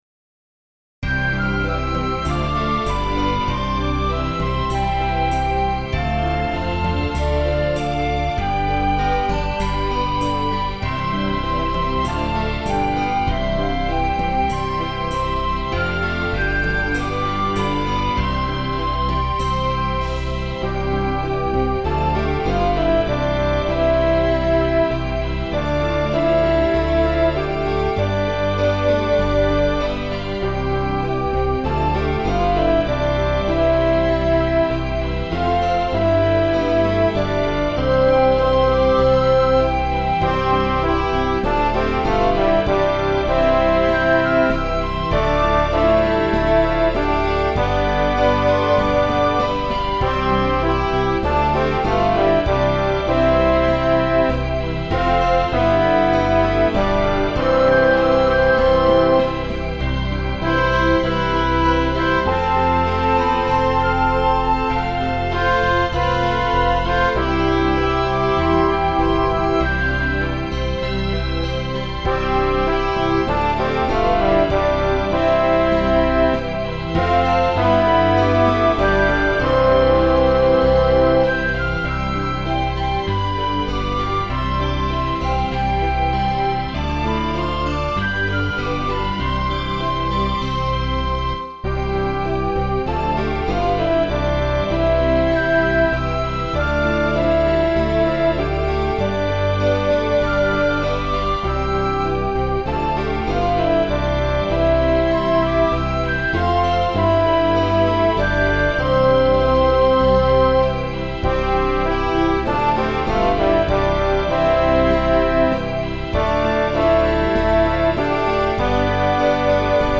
MP3 Instrumental